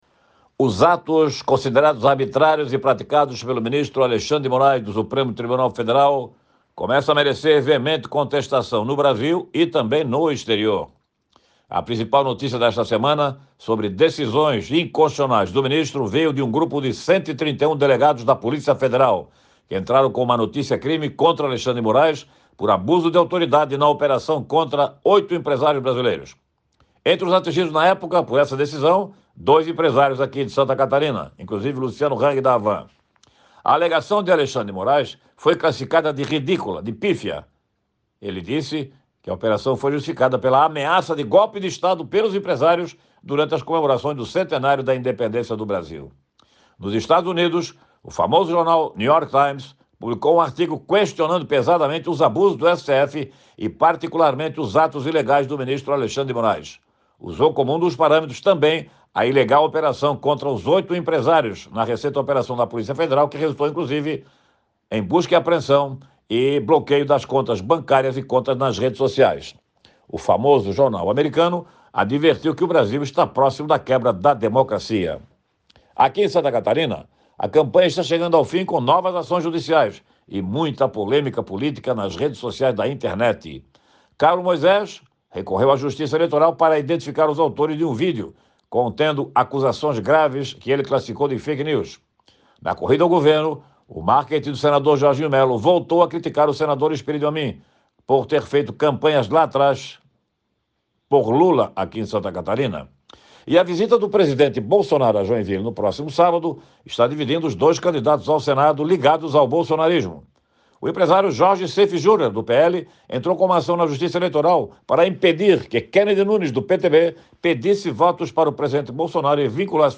Jornalista ressalta sobre atos praticados pelo Supremo Tribunal Federal no comentário em áudio desta terça-feira (27)